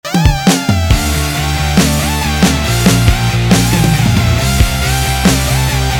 Помогите накрутить lead